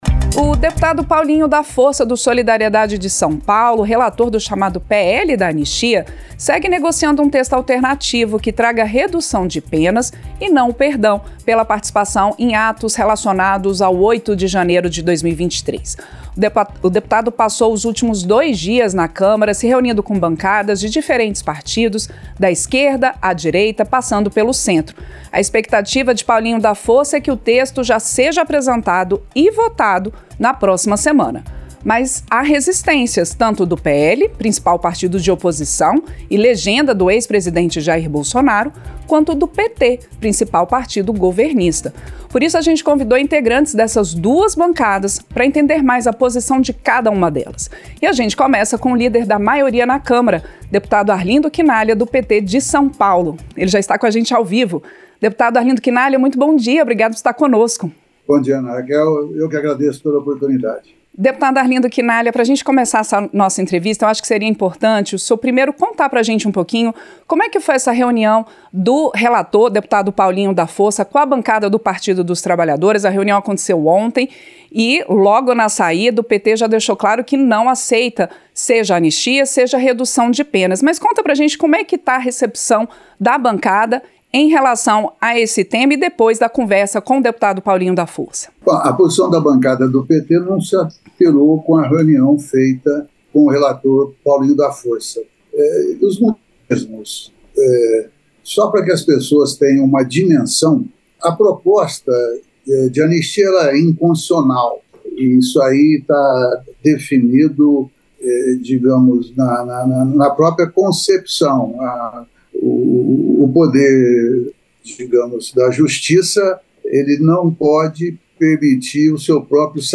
Entrevista - Dep. Arlindo Chinaglia (PT-SP)